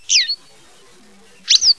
Gulärla (Motacilla flava).
gularla.wav